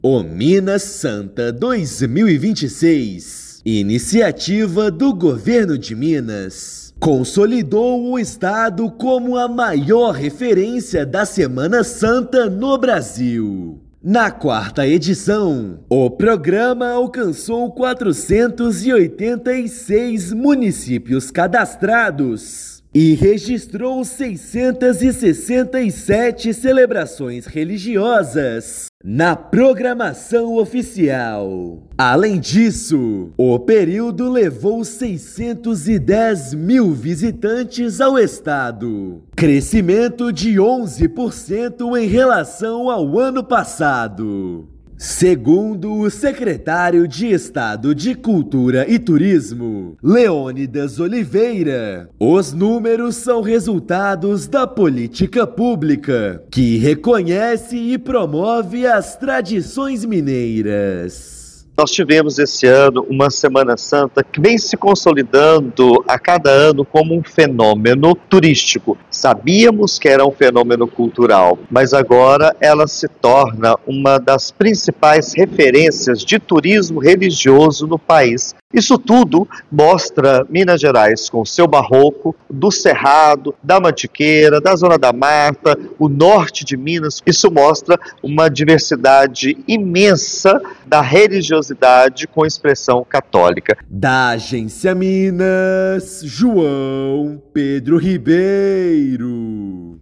[RÁDIO] Minas Santa cresce em 2026 e consolida Minas como a maior referência da Semana Santa no Brasil
Programa amplia alcance, mobiliza 610 mil visitantes, registra 667 ritos e reafirma a força da fé, da cultura e da tradição em todo o território mineiro. Ouça matéria de rádio.